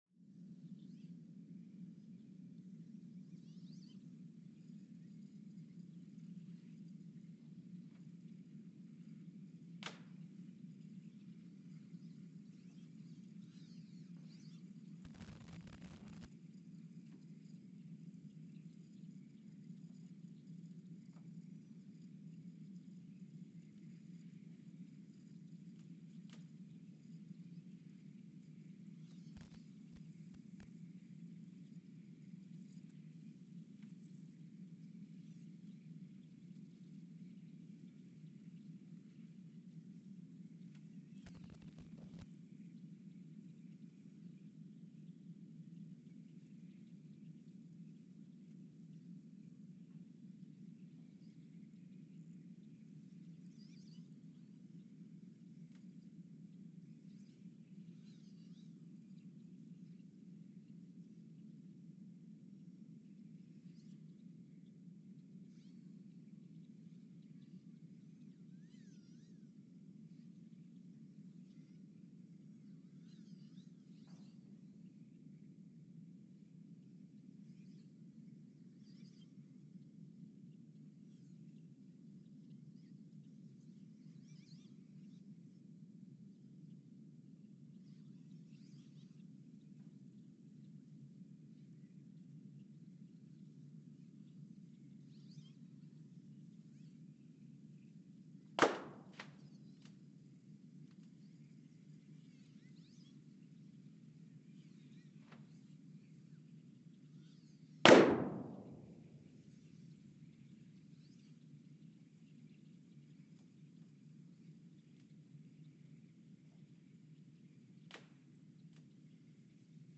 The Earthsound Project is an ongoing audio and conceptual experiment to bring the deep seismic and atmospheric sounds of the planet into conscious awareness.
Speedup : ×900 (transposed up about 10 octaves)
Loop duration (audio) : 11:12 (stereo)